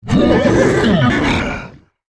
minotaur_die.wav